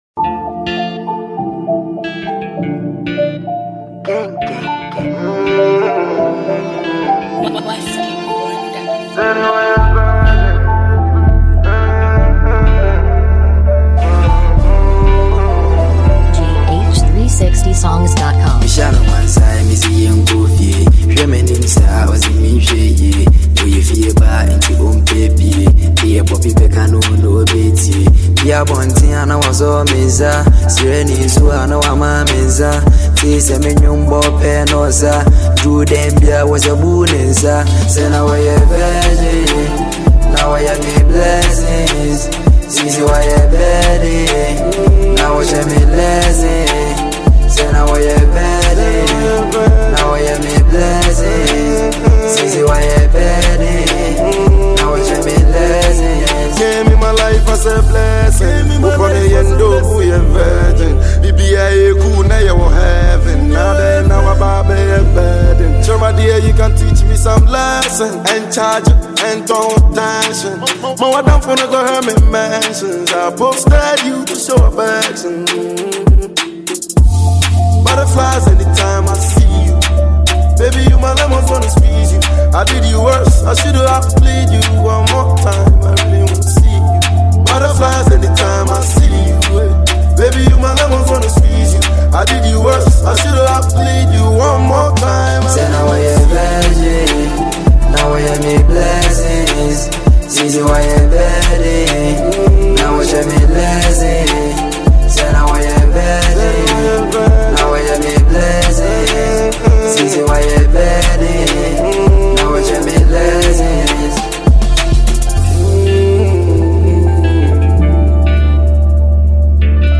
Ghana Music
a very nice catchy beat with a very clear voice
Asaakaa MP3 song